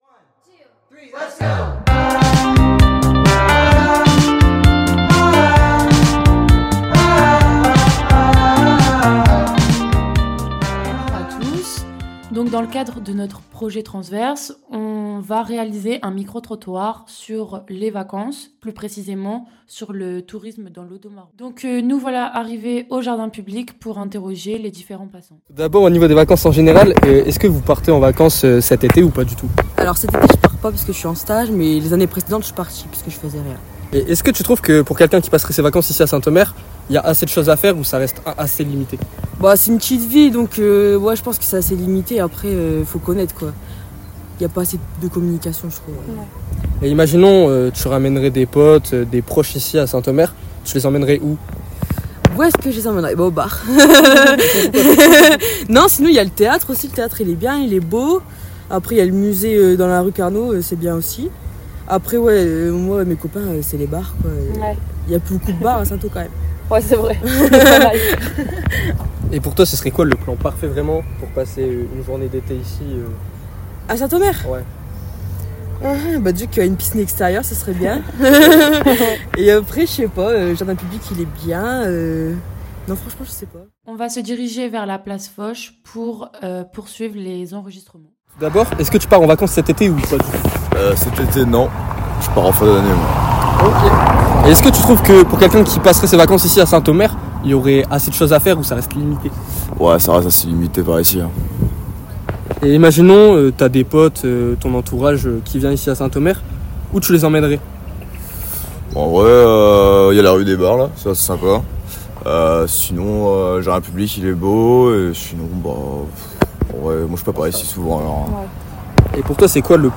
Enregistrement complet + Interview